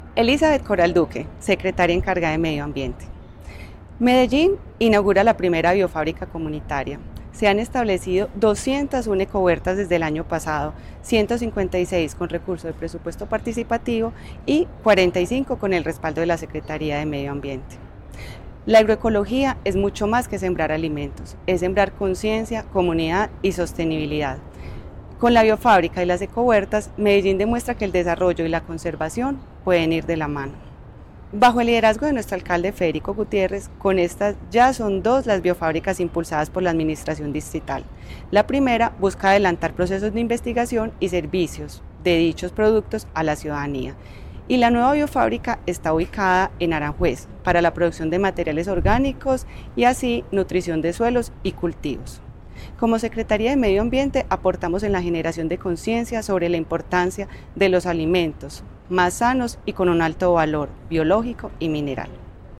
Palabras de Elizabeth Coral Duque, secretaria (e) de Medio Ambiente